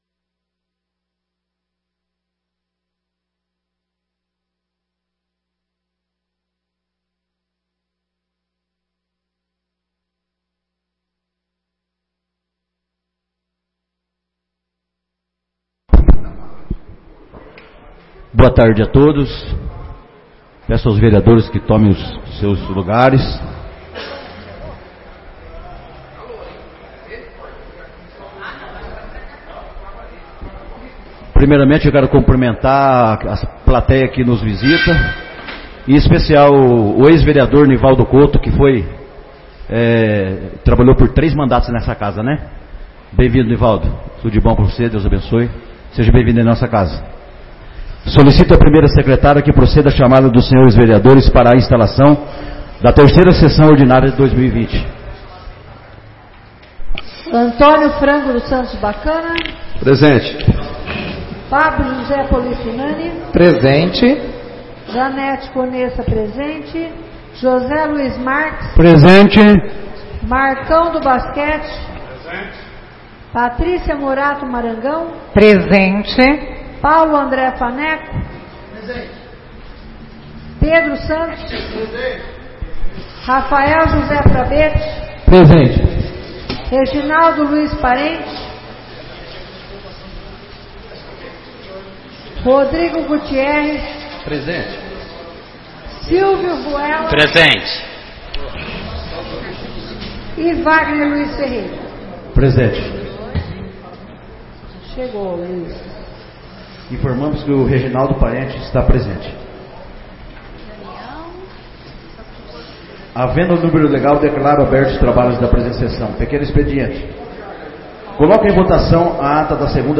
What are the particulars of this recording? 3ª Sessão Ordinária de 2020